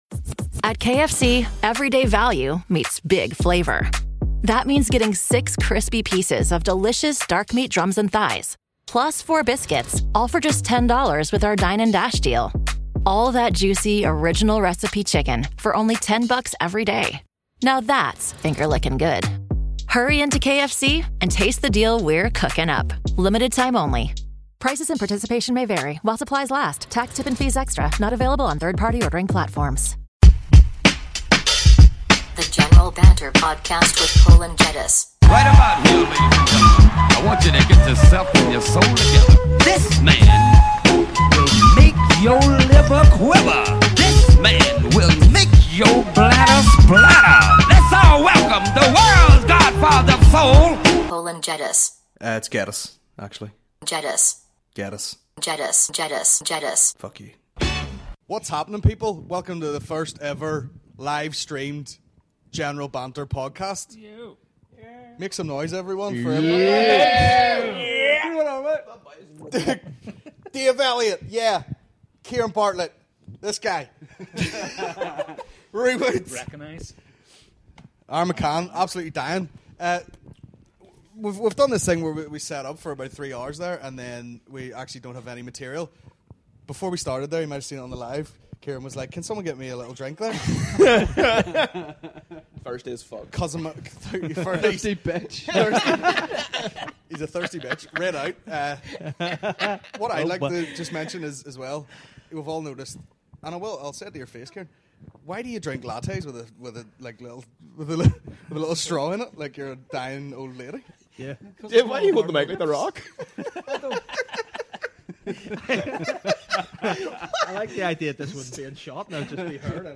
The General Banter Squadcast - Ep. 1 General Banter Podcast General Banter Podcast Comedy 4.8 • 1.1K Ratings 🗓 26 June 2016 ⏱ 43 minutes 🔗 Recording | iTunes | RSS 🧾 Download transcript Summary This is the first of the General Banter Squadcasts live streamed every Wednesday.